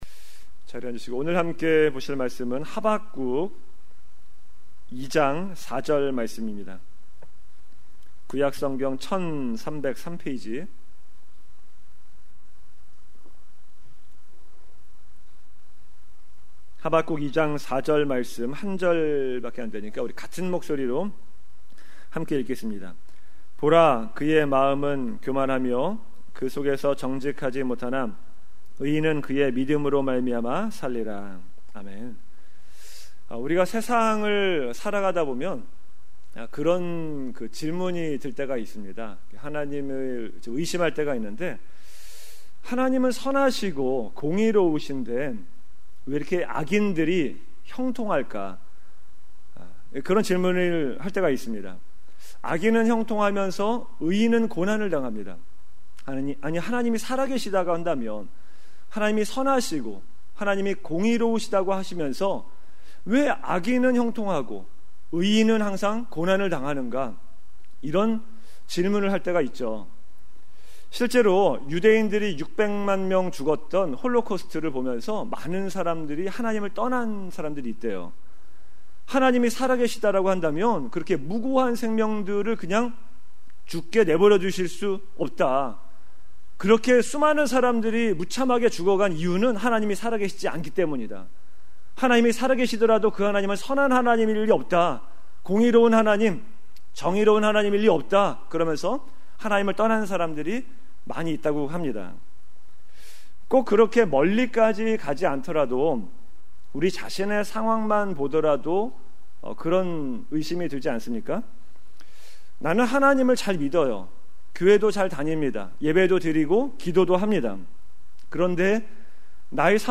Like this: Like Loading... 2019년 금요설교 Uncategorized 2026년 전교인 수련회 찬양 플레이 리스트 각종 신청서 2025년 헌금내역서 신청서